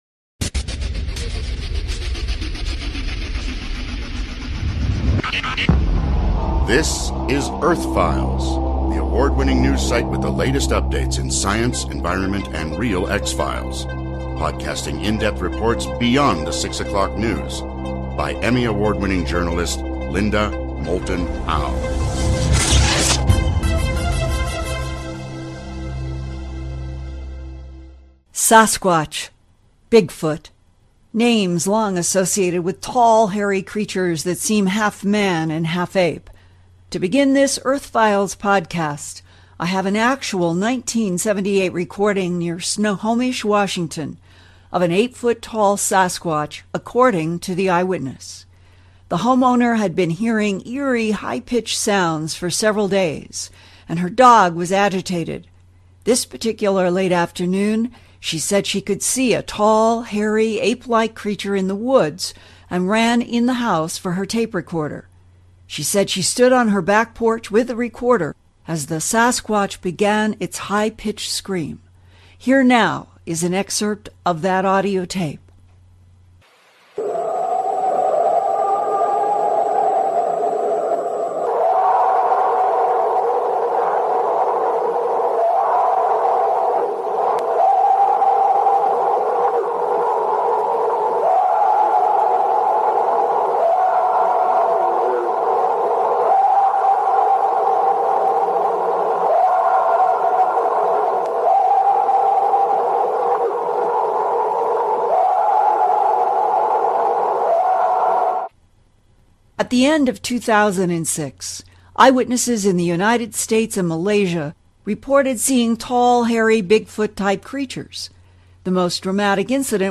Hear the eerie screams of a tall, hairy, humanoid creature seen and recorded by an eyewitness in Snohomish, Washington.
Includes discussion with a Professor of Anatomy and Anthropology about his own investigations of the illusive Sasquatch/Bigfoot.